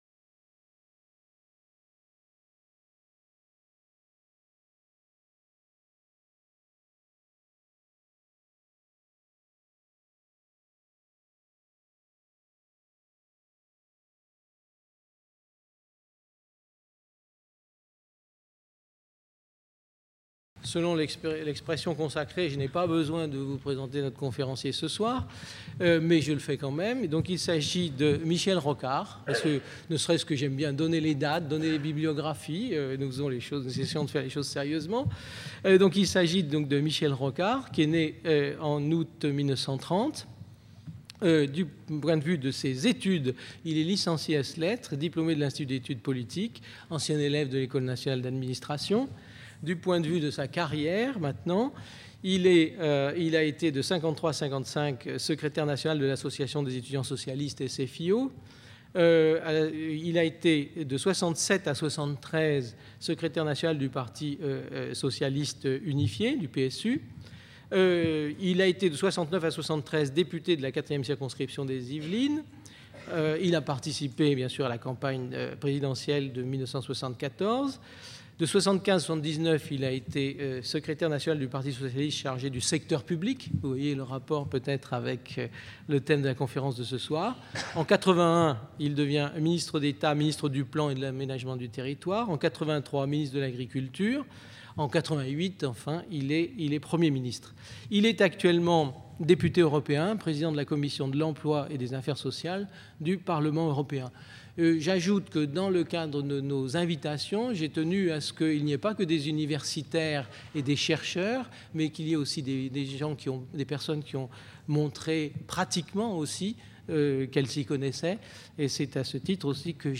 Conférence donnée le 9 juin 2000 par Michel ROCARD. Le concept de service public en France est étroitement lié à l'idée de l'intérêt général et repose sur trois définitions complémentaires : au plan juridique, le service public relève de trois principes : la continuité, l'égalité, l'adaptabilité ; la définition économique repose sur le fait que les services publics permettent de prendre en compte des phénomènes que le marché ignore : gestion du long terme, investissements lourds non immédiatement rentables, préservation d'un bien rare ou précieux et gestion de l'espace ; au-delà de ces définitions juridiques et économiques le concept de service public est de nature politique et relève d'un mode d'organisation sociale.